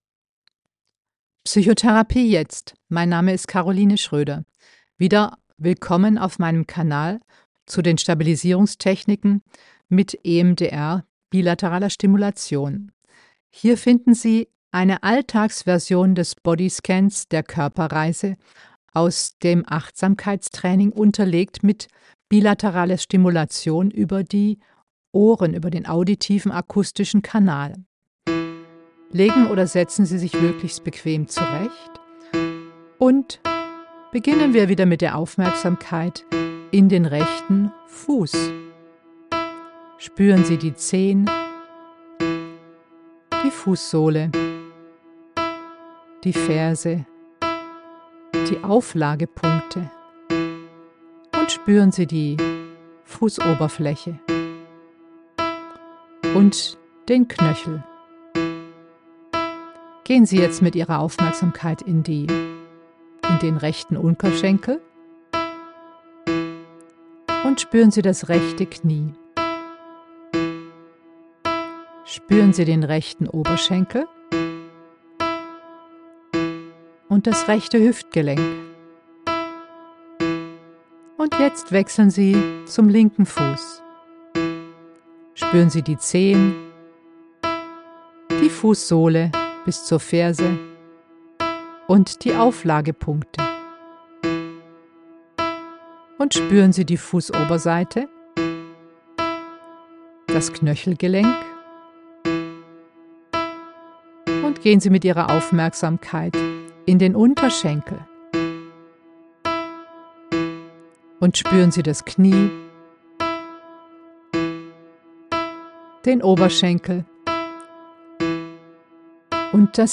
Mit diesem nicht ganz regelmäßigen Tönen wird das Gehirn